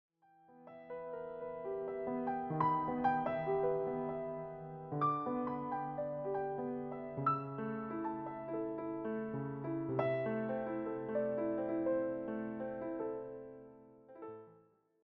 presented as relaxed piano interpretations.